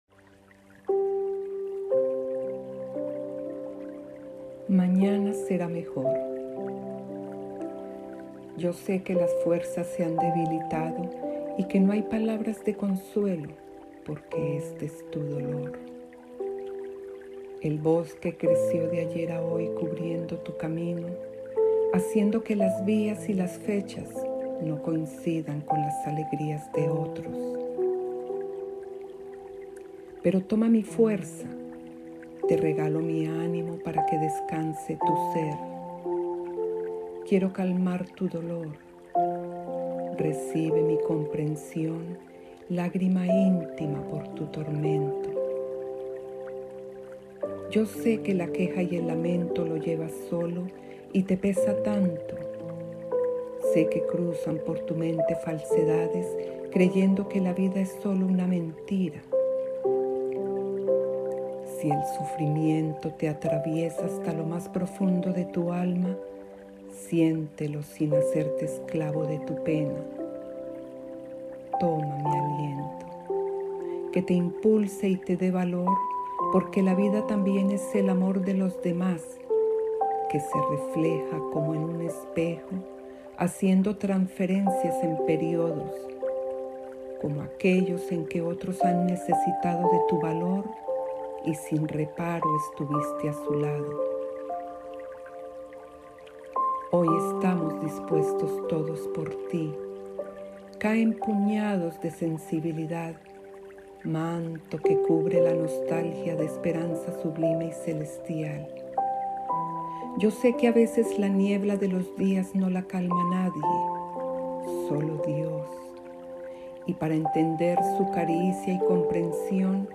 Lectura